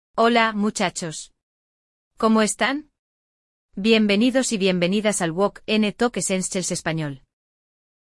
Hoje, você vai ouvir uma conversa numa empresa.